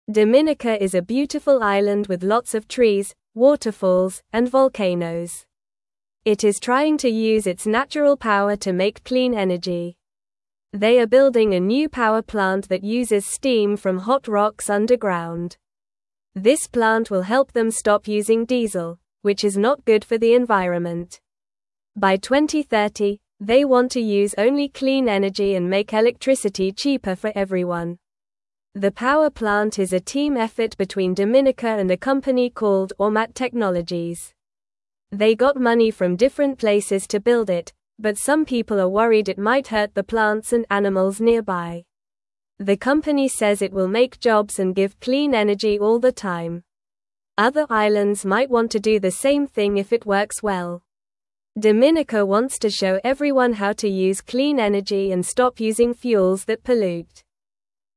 Normal
English-Newsroom-Beginner-NORMAL-Reading-Dominicas-Clean-Energy-Plan-for-a-Bright-Future.mp3